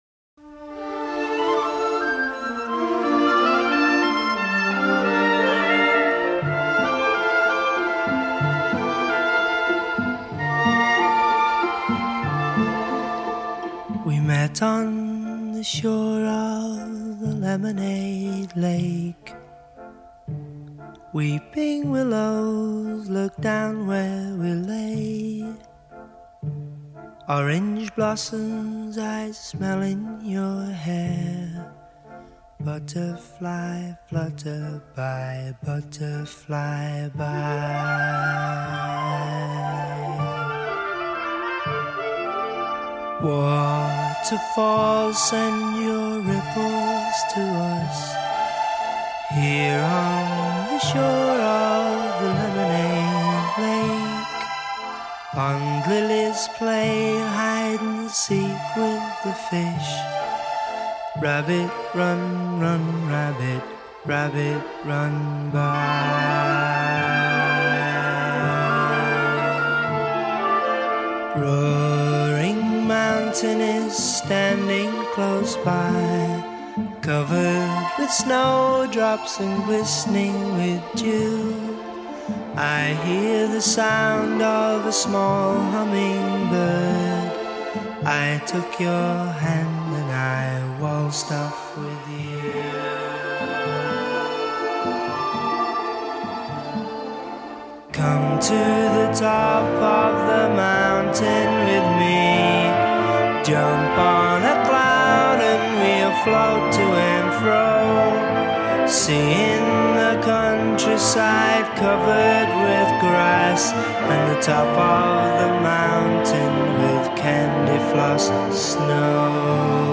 Now we’re into full-on psychedelia.